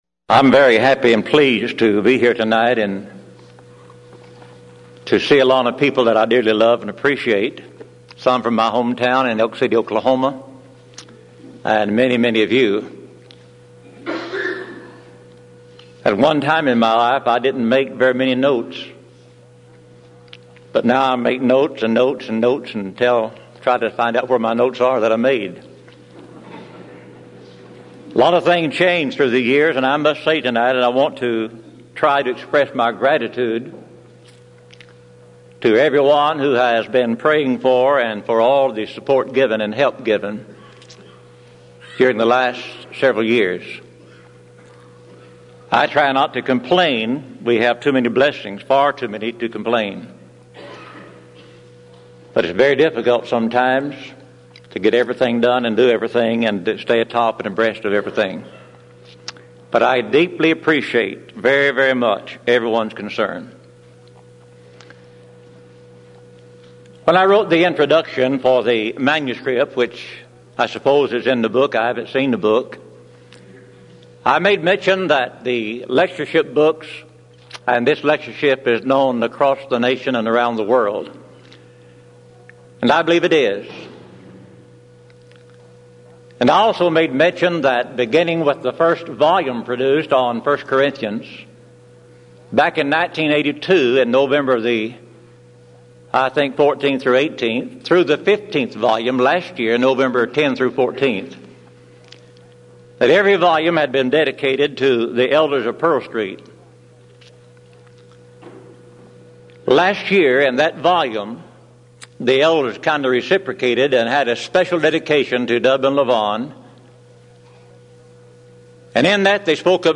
Event: 16th Annual Denton Lectures Theme/Title: Studies In Ephesians
lecture